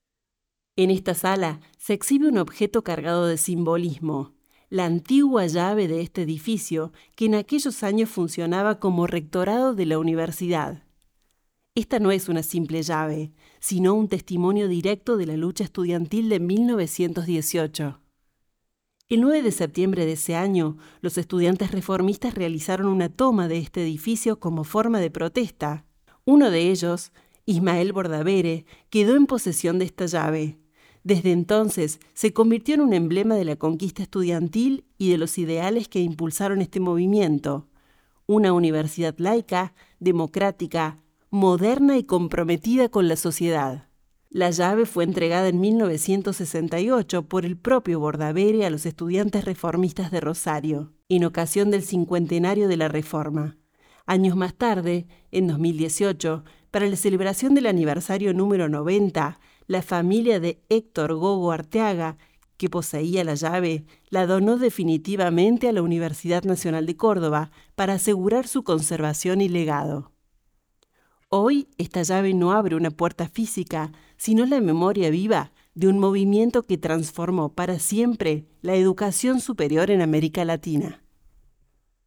[Audioguía]